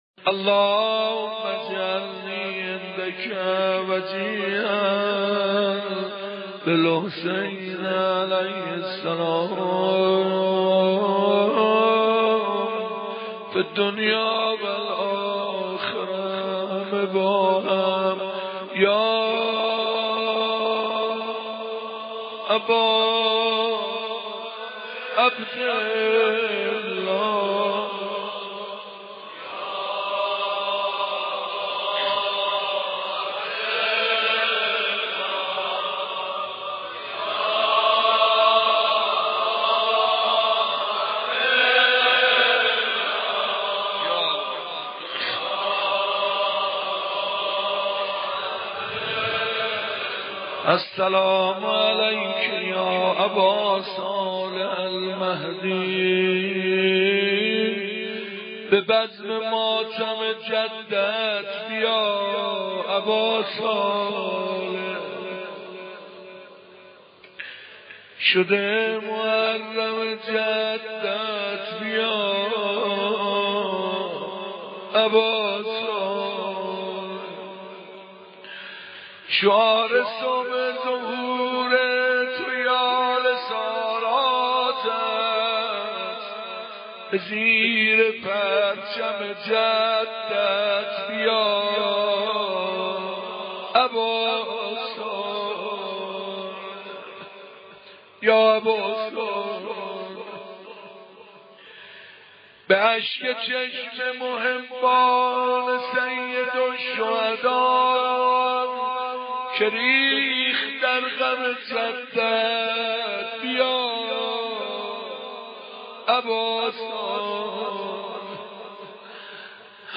مناجات با امام زمان ع
مناجات شب تاسوعا - به بزم ماتم جدّت ، بیا اباصالح